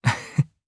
Siegfried-Vox_Happy1_jp_b.wav